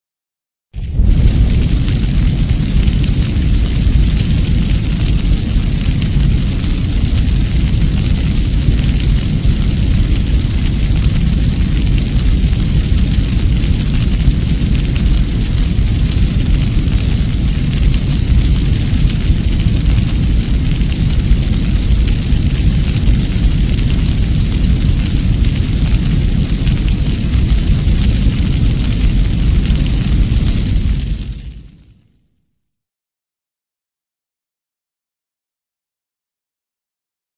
MudTankerGroundNoise.mp3